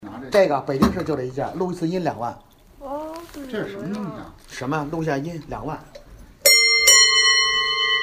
黄包车的车铃，德国原装，声音非常好听